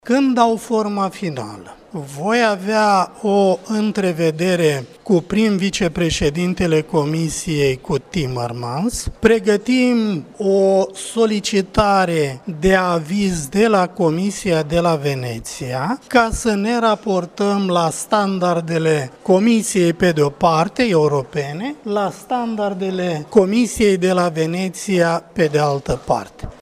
Înainte de a elabora forma finală a pachetului de legi pe justiţie, se va solicita un aviz din partea Comisiei de la Veneţia, iar pachetul de legi va fi prezentat Comisiei Europene, a adăugat Tudorel Toader: